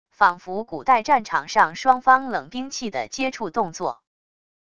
仿佛古代战场上双方冷兵器的接触动作wav音频